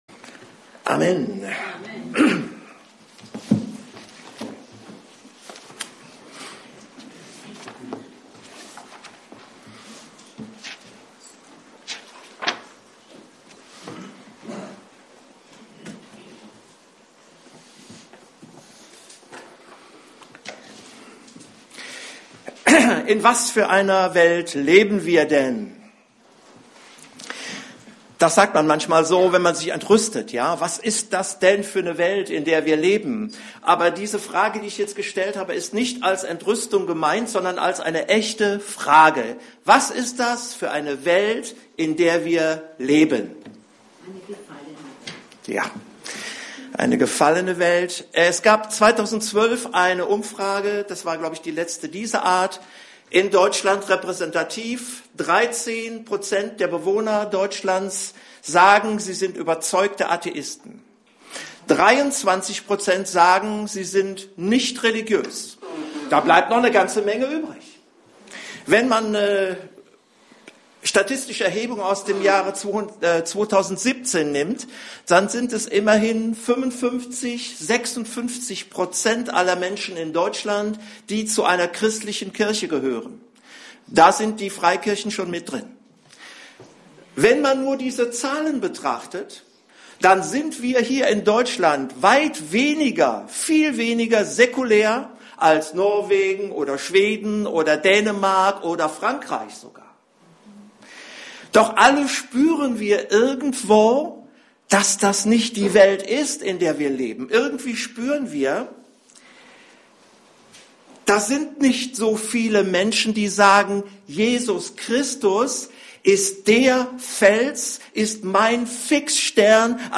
Passage: 2. Korinther 6, 14-18 Dienstart: Predigt